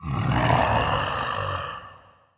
roar.ogg